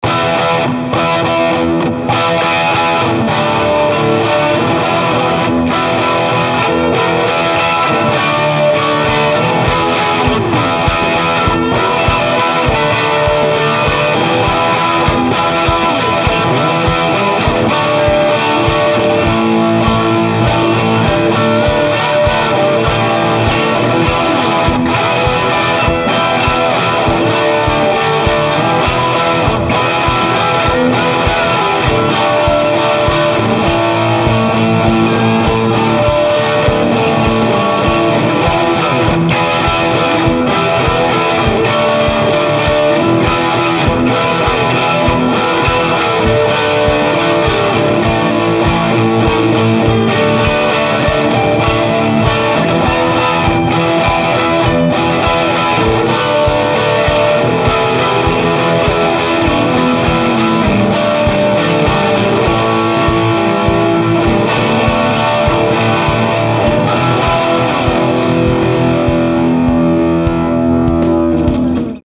TONE SAMPLES - All clips plugged straight into amp unless noted.
Test if I could create an authentic live feel at less than one
thousandth  of a watt. (I could smell the booze!)